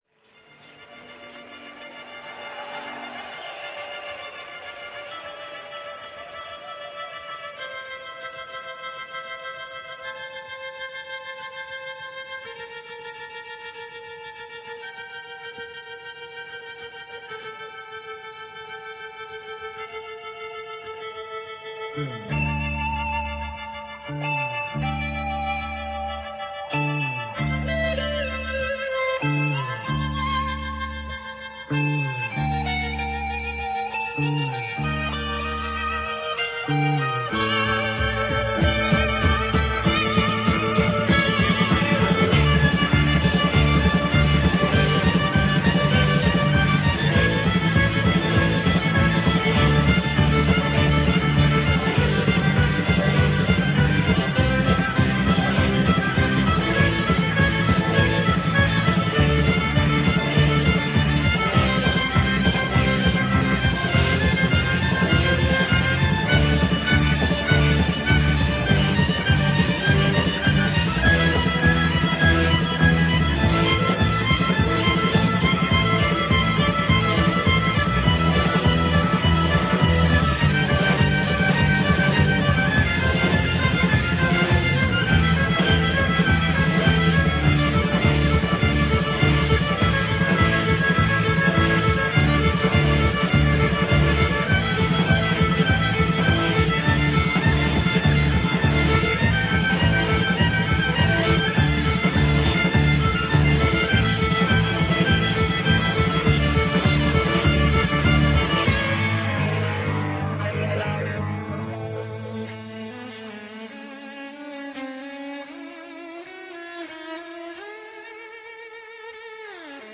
stereo, 4.0 Khz, 20 Kbps, file size: 298 Kb